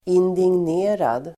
Ladda ner uttalet
indignerad.mp3